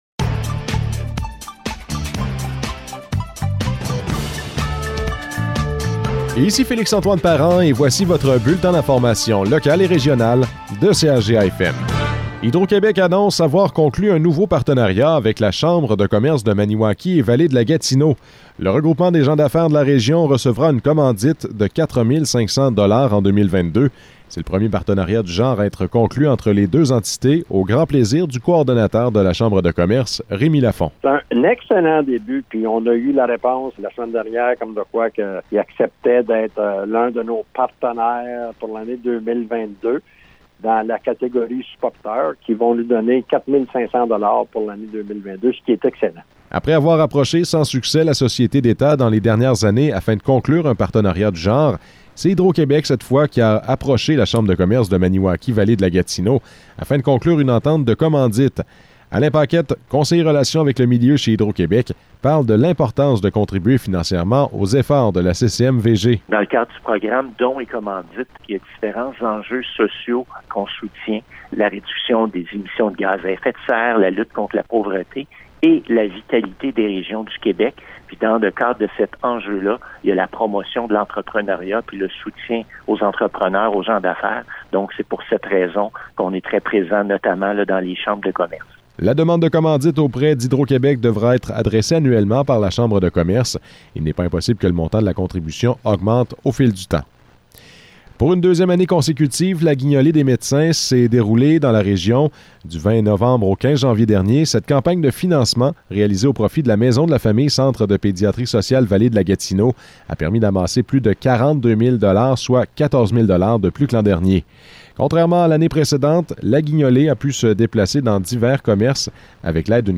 Nouvelles locales - 25 janvier 2022 - 12 h